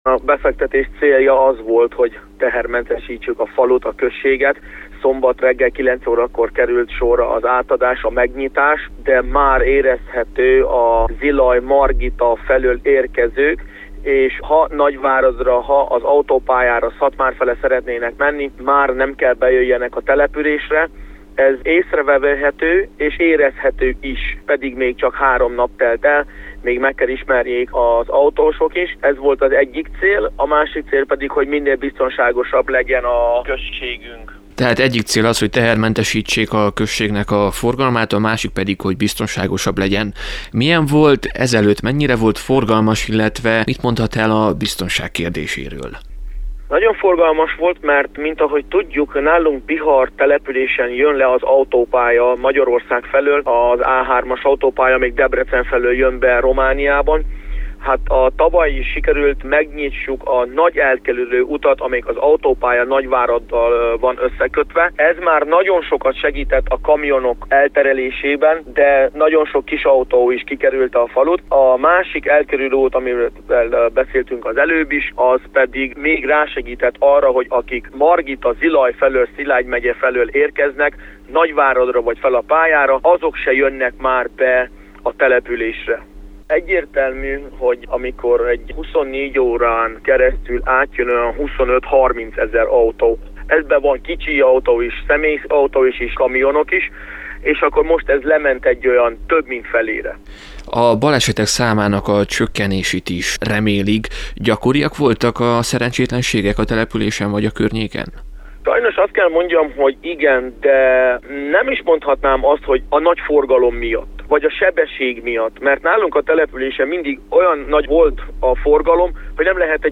Rádiónknak nyilatkozva Bihar Község polgármestere, Szilágyi Zoltán kiemelte, hogy a tavaly már átadtak egy nagyobb terelőutat, de a szombaton megnyitott szakasz is érezteti hatását.